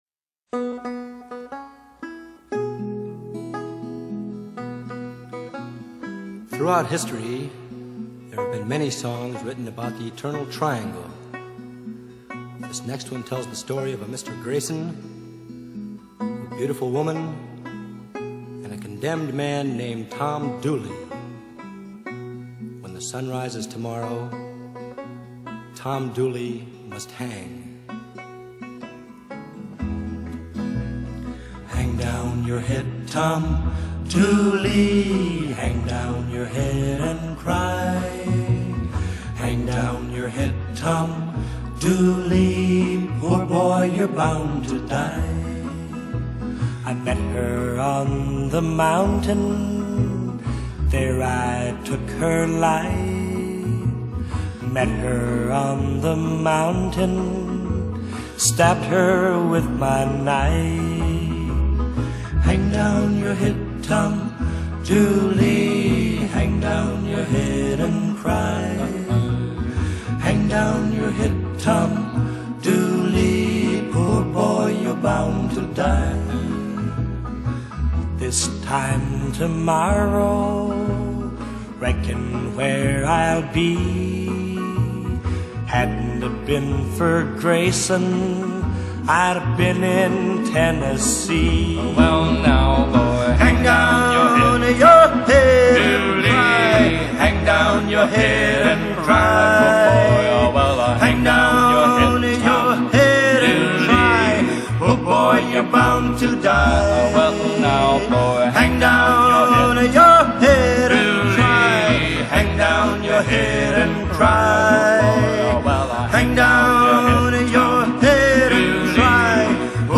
Country | MP3 CBR 320 Kbps | 139 MB | Frontcover
American folk and pop music group